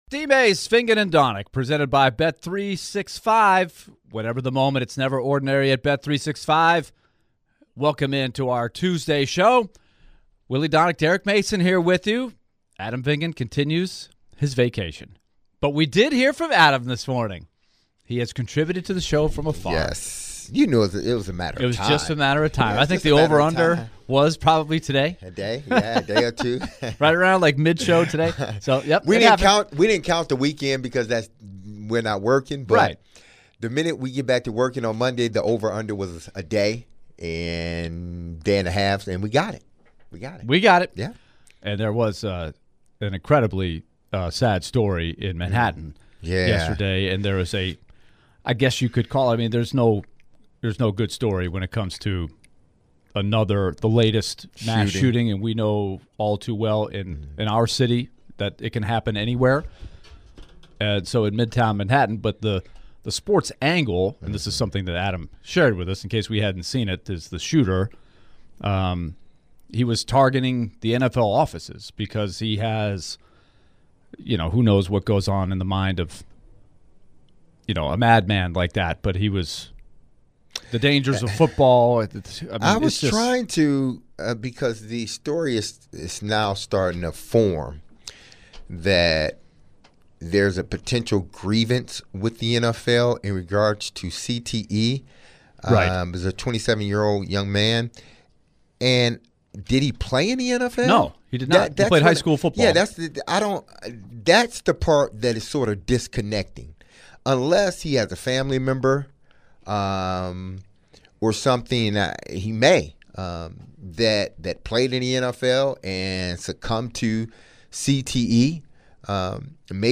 They then begin to discuss the transition into pads in the Titans' training camp, giving their thoughts. ESPN NFL Analyst Tim Hasselbeck joins DVD to discuss Titans training camp and the transition into pads, concerns for players' health with the heat, Titans' death, and more. To end the hour, they give their reactions to Tim Hasselbeck's thoughts and get listeners' reactions.